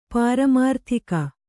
♪ pāramārthika